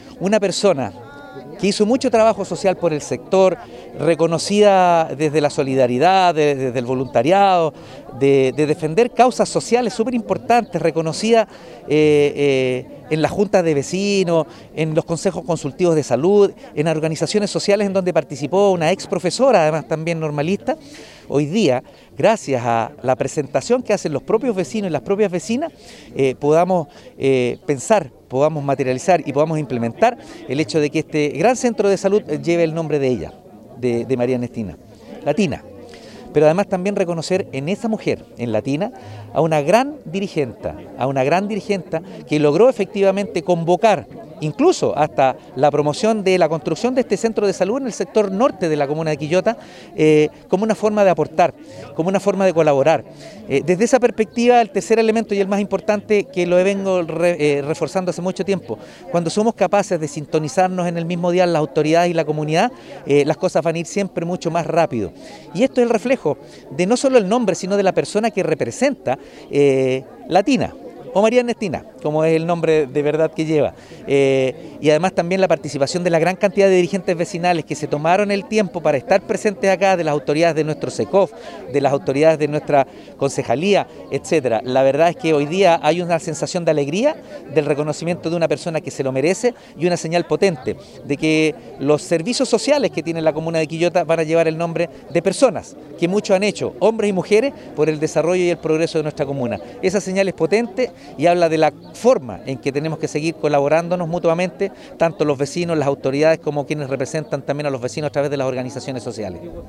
El cambio de nombre del centro asistencial, se realizó en una ceremonia encabezada por el alcalde Óscar Calderón Sánchez, acompañado de concejalas, familiares de la fallecida dirigente, autoridades de SaludQuillota, dirigentes, vecinos y vecinas.
alcalde-oscar-calderon-.mp3